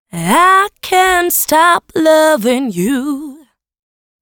Stimmen für R&B, Reggae, House & Co.
Enthalten sind rund 1.000 Samples für Pop, R&B, Dub, Reggae, House und mehr – die englischsprachigen Vocal Samples sind vor allem für Tanzmusik moderner Genres geeignet.
Die Klangqualität ist erwartungsgemäß gut, die Dateinamen sind aussagekräftig (meist beinhalten sie genau das Gesate/Gesungene/Gehauchte/…) und die Formate sind zahlreich – siehe Infokasten.
I-Cant-Stop-Loving-You.mp3